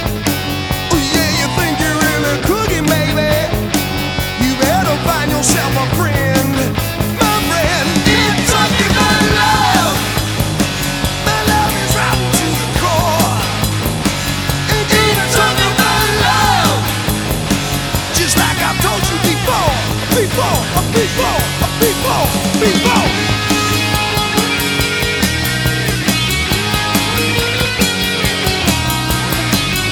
• Hard Rock